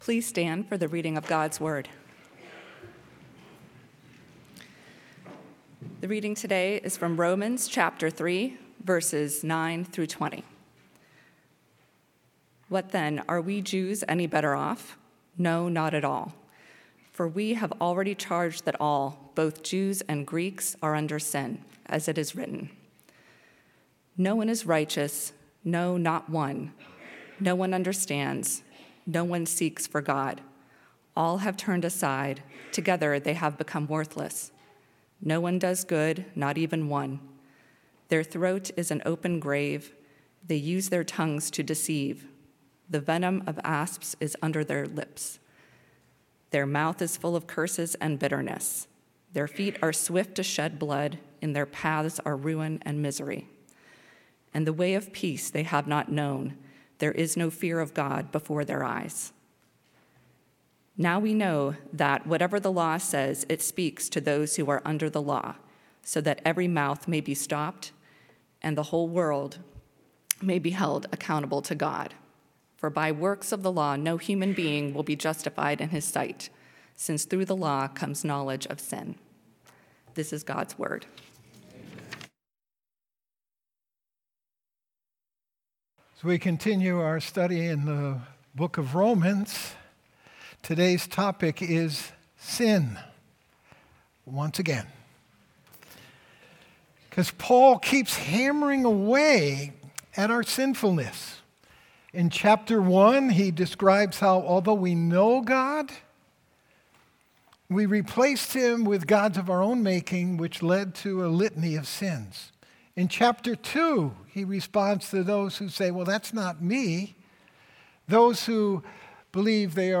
Sermons
The preaching ministry of Westgate Church (Weston, MA)